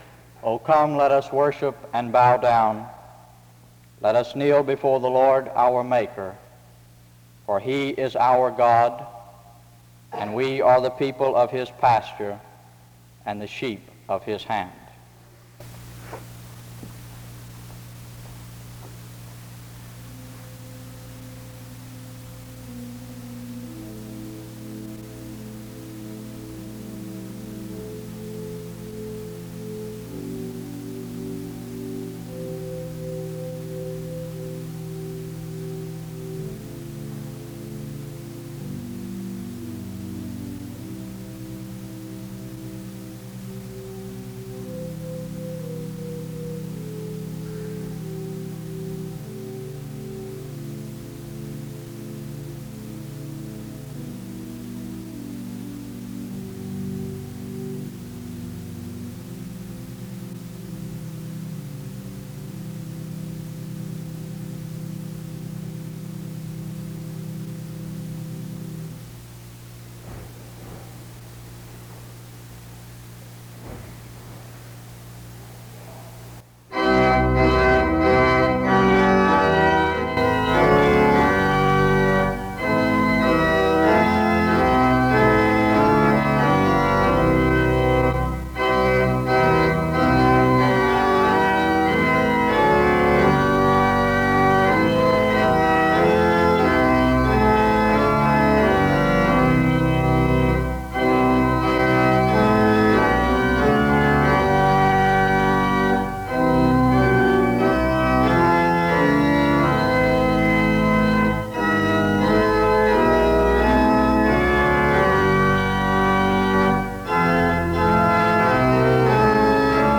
The service begins with prayer and music from 0:00-2:10. A prayer is offered from 2:29-4:04. An introduction to the speaker is given from 4:14-7:14.